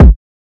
Kick 001.wav